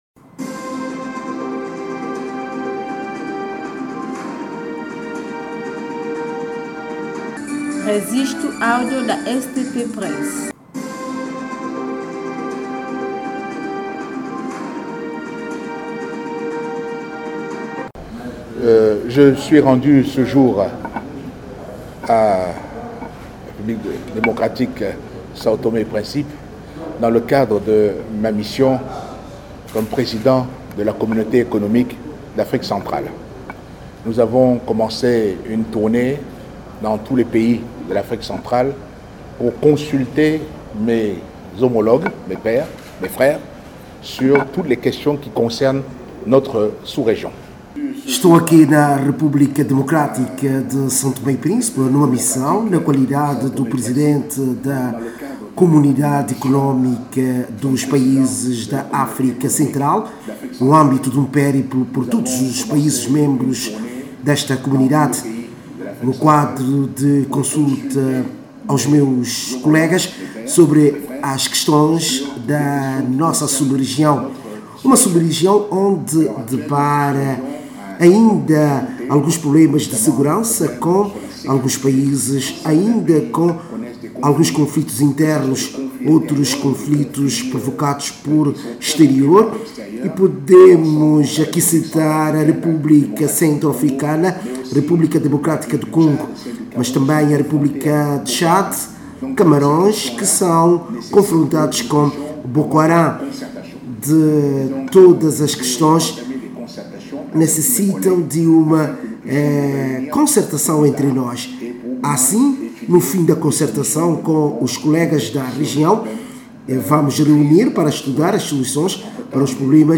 Em declarações a imprensa, Ali Bongo Ondimba, na qualidade de presidente da Comunidade Económica dos Estados da África Central, CEEAC, sublinhou ter-se deslocado ao arquipélago para “ analisar em conjunto com o seu homólogo são-tomense as questões da nossa comunidade”.